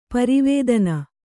♪ pari vēdana